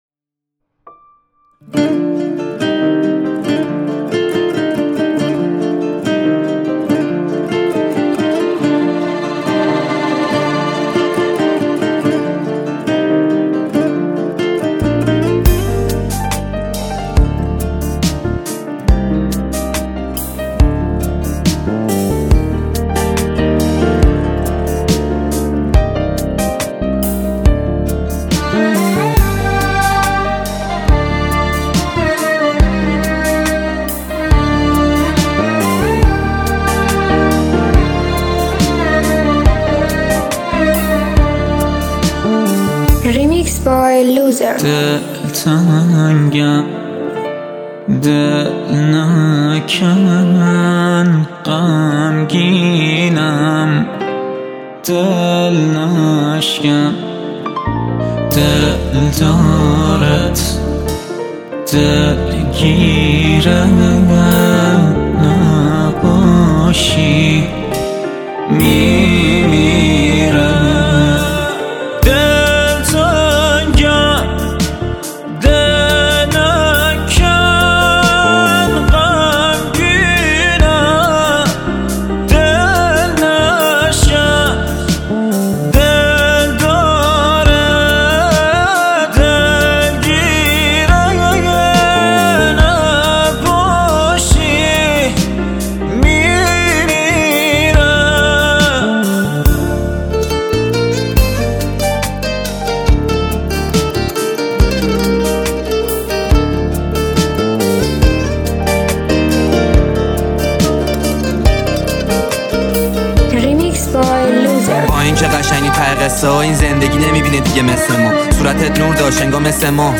(Version Rap)